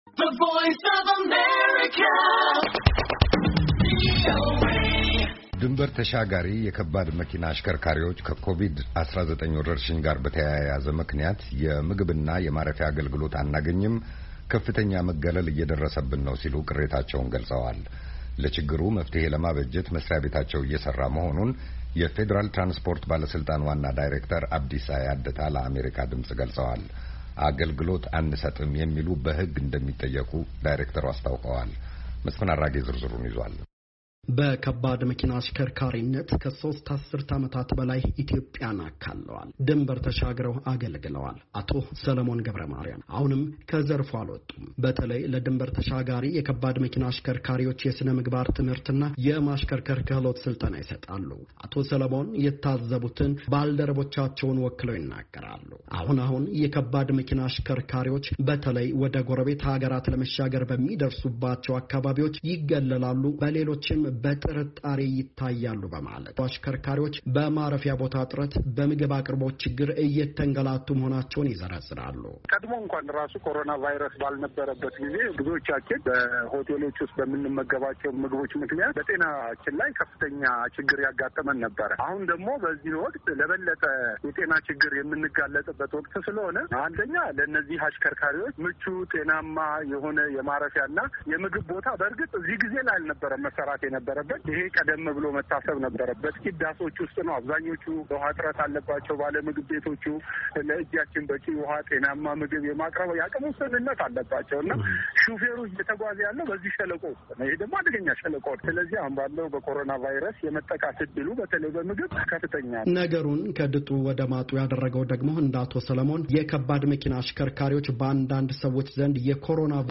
ለችግሩ መፍትኄ ለማበጀት መሥሪያ ቤታቸው እየሠራ መሆናን የፌዴራል ትራንስፖርት ባለሥልጣን ዋና ዳይሬክተር አብዲሳ ያደታ ለአሜሪካ ድምፅ ገልፀዋል፡፡